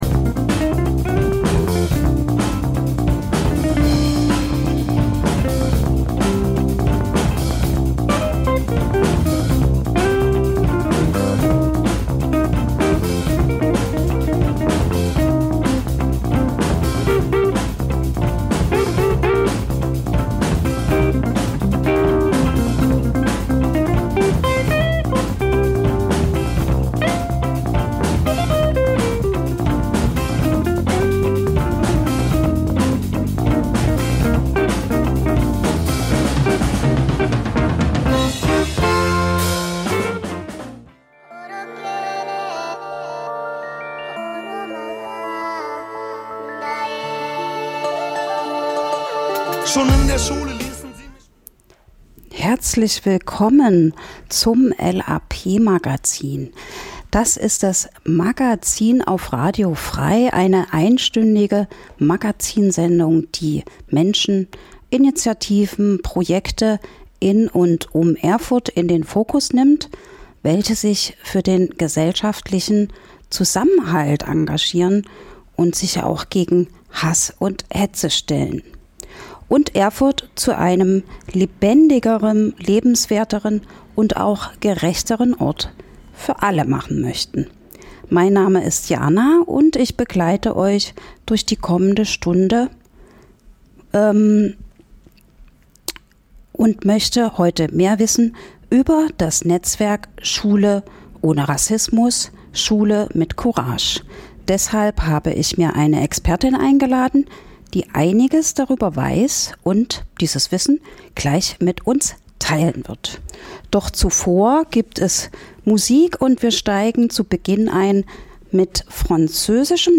Interviews zu aktuellen Themen
Musikrubrik "Coole Cover"